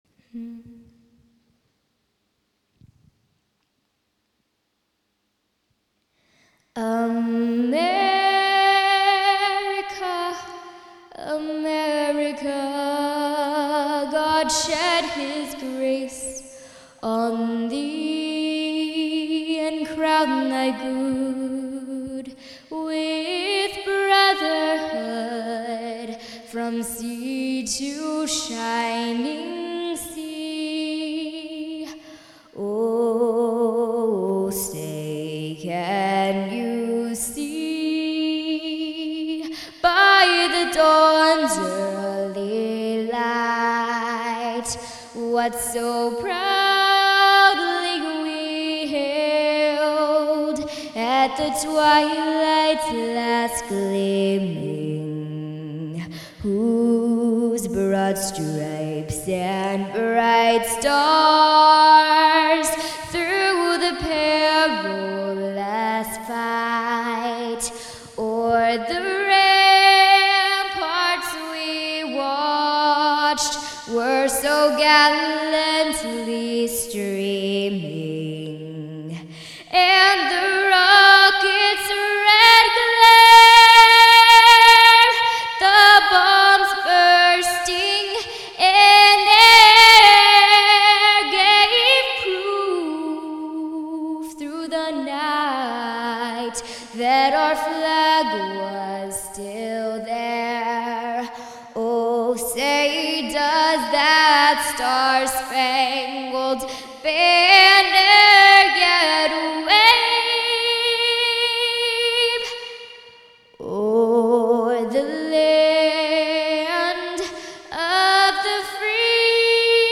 Perhaps this recoding of me singing the Star-Spangled Banner can provide some inspiration and healing as we come together as a nation and world to mourn the loss of life.